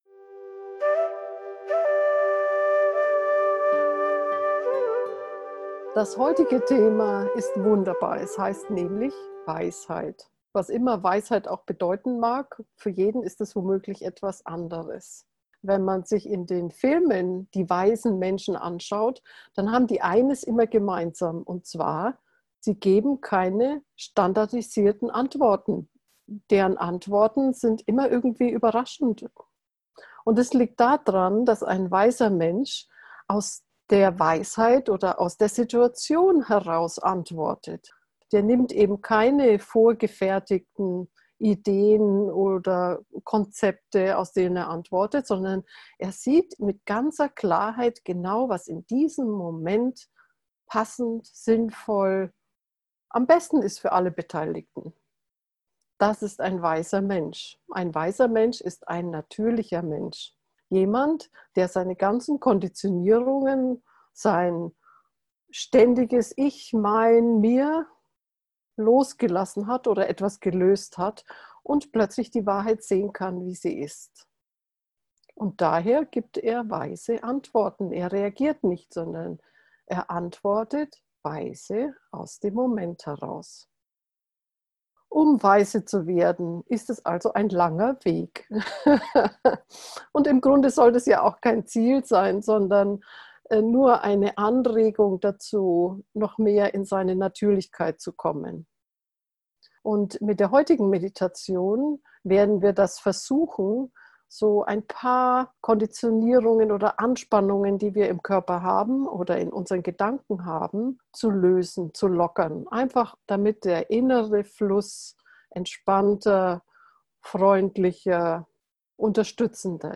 Mit dieser geführten, tantrischen Meditation durchdringen wir das, was die weise Sicht behindert, wir beginnen mit dem Körper, in dieser Folge mit dem Bauch. Nach 5 Minuten Einleitung beginnt die etwa 10minütige, geführte Meditation.
weisheit-gefuehrte-tantrische-meditation-fortgeschrittene